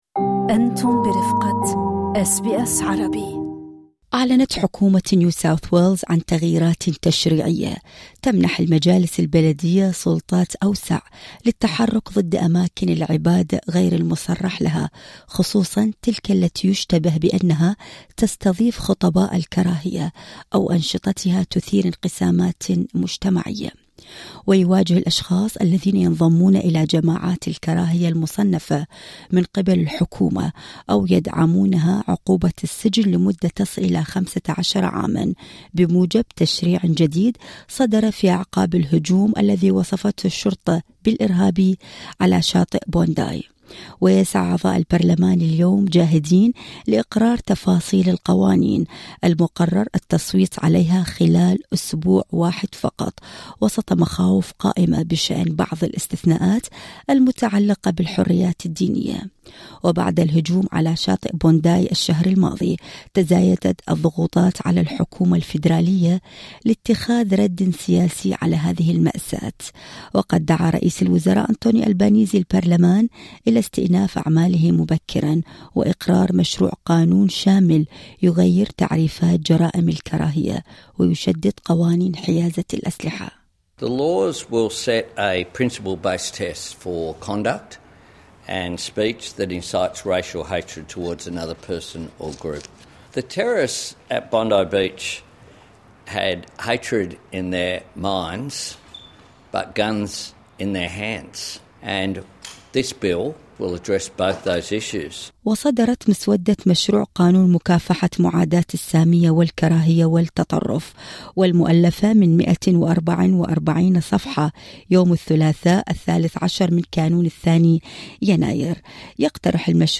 تقرير مفصل يتضمن ردود فعل رسمية ومقابلة